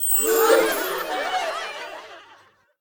wow_reward_01.ogg